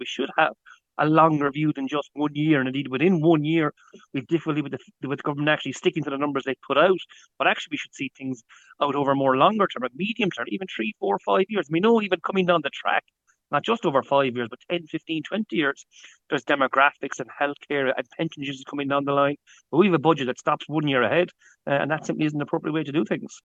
The Irish Fiscal Advisory Council chairman Seamus Coffey says it’s as if 2027 and 2028 don’t exist: